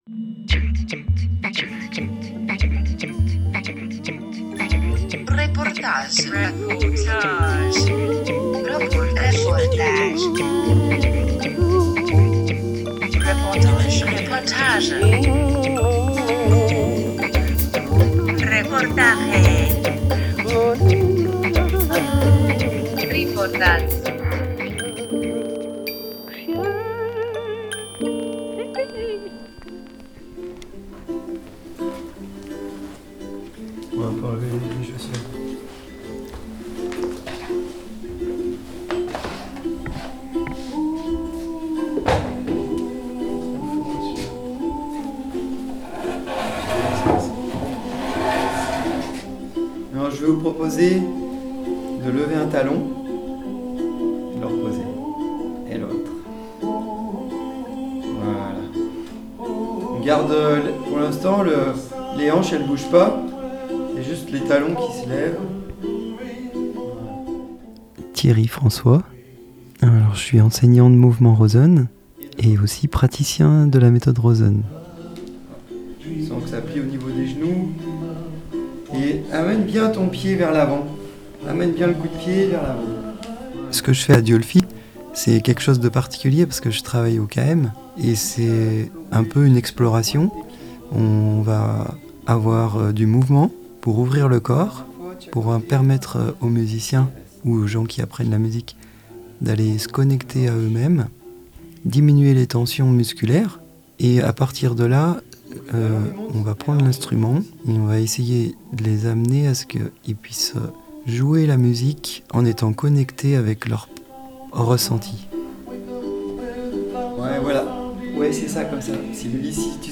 14 février 2022 8:00 | reportage